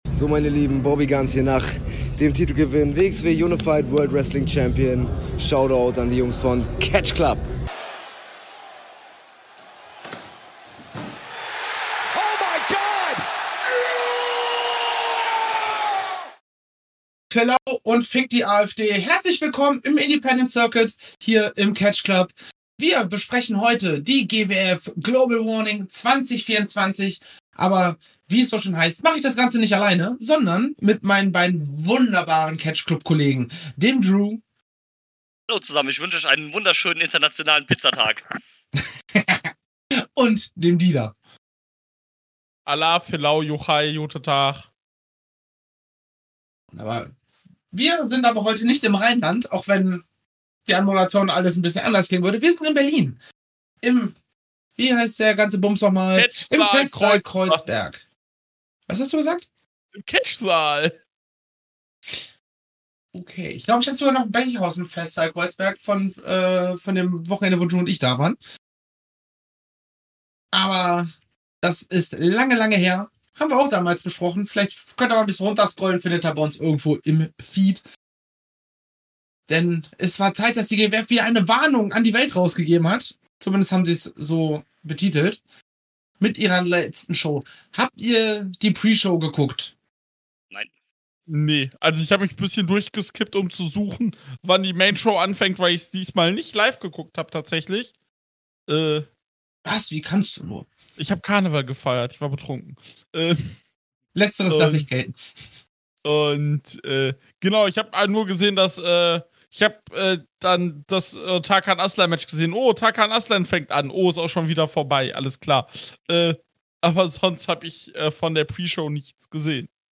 Wir befinden uns zum 2. Mal in diesem Jahr im Catchsaal in Kreuzberg. GWF hatte eine Globale Warnung und wir sprechen über diese in unserer 3er Runde.